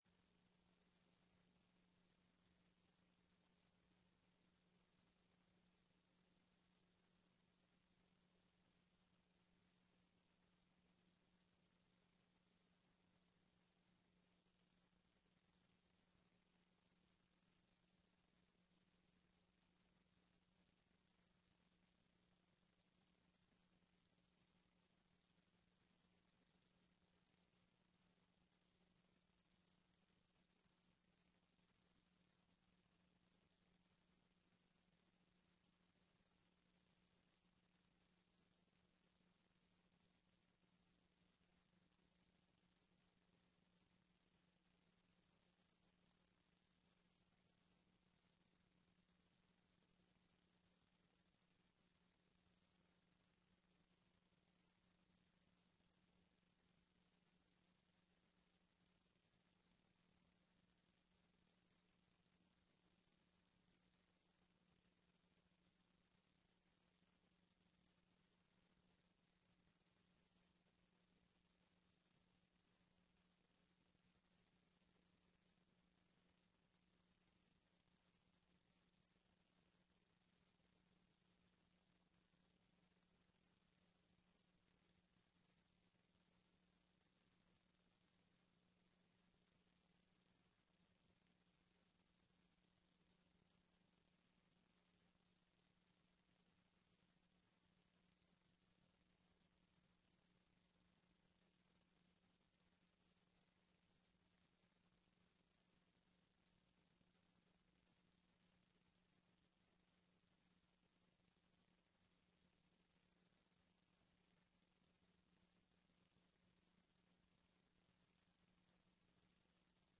TRE-ES sessão do dia 20/11/15